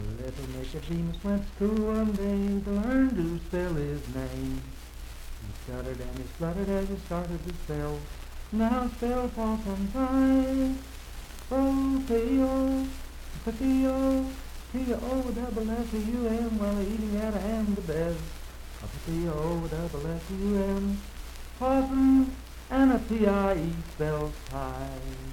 Unaccompanied vocal music
Minstrel, Blackface, and African-American Songs
Voice (sung)
Marlinton (W. Va.), Pocahontas County (W. Va.)